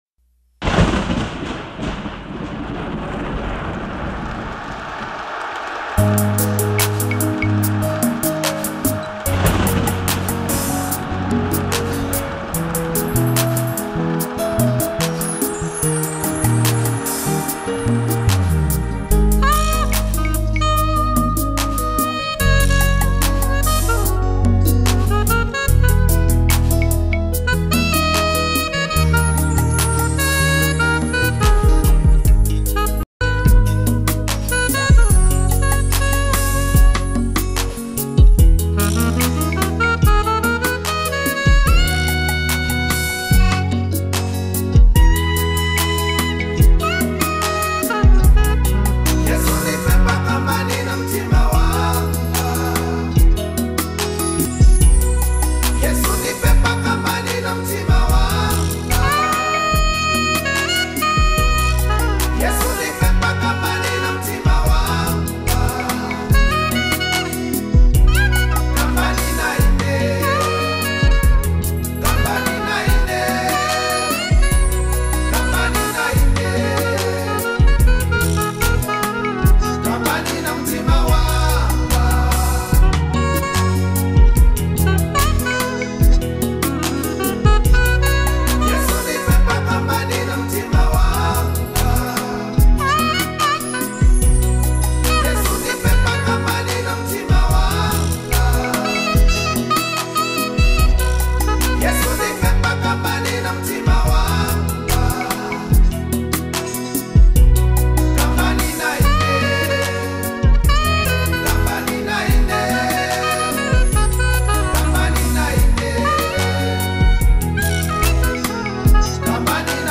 a powerful worship song
Genre: Gospel